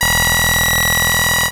BadTransmission3.wav